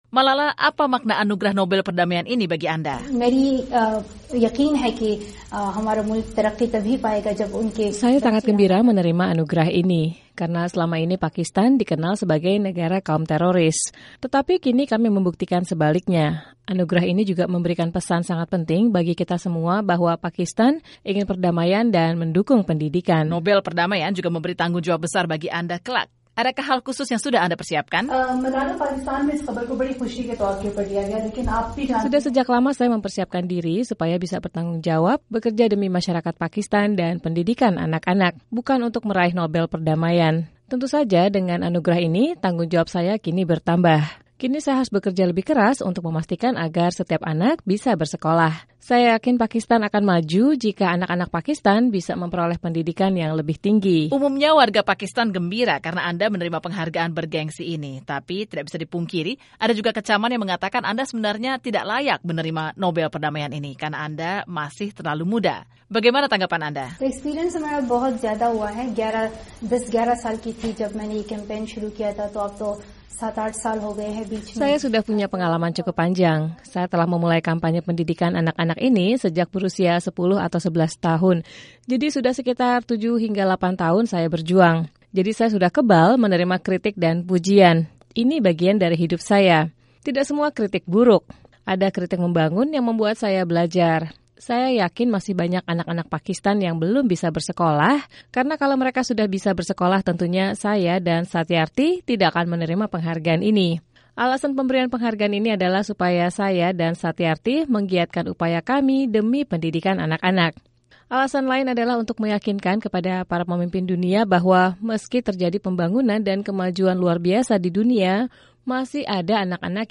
Seusai upacara di Oslo, Norwegia, penerima Nobel Perdamaian termuda dalam sejarah ini diwawancarai oleh VOA.